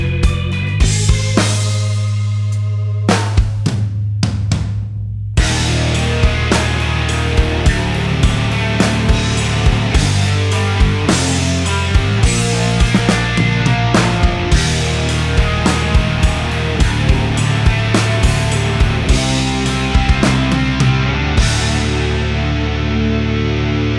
Minus Main Guitar Rock 5:22 Buy £1.50